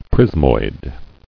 [pris·moid]